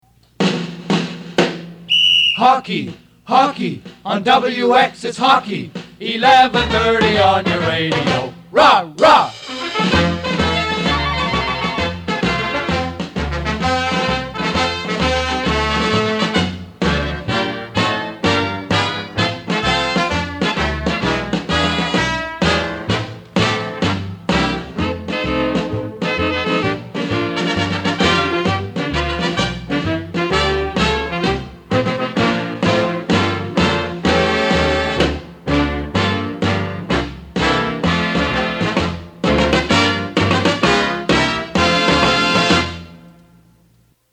JINGLES AND MORE AUDIO